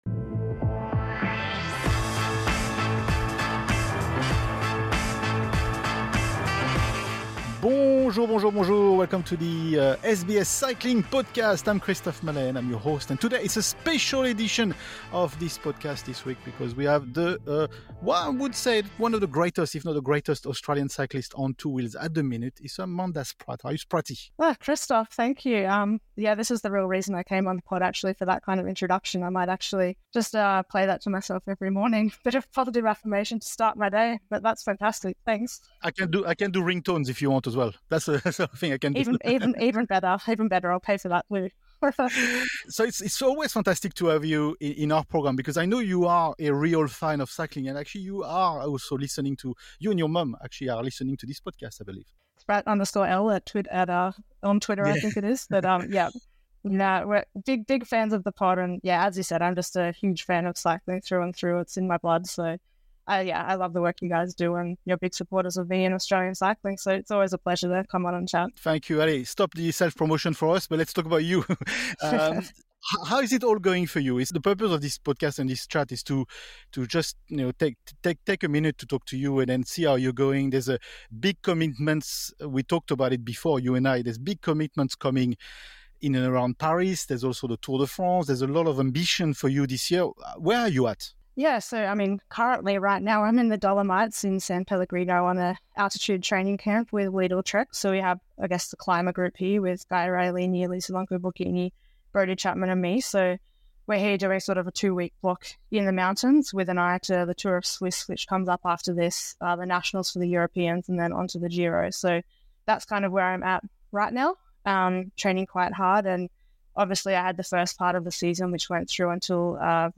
Spratt joins us from the picturesque Dolomites in San Pellegrino, where she's currently engaged in an altitude training camp with her team, Lidl-Trek.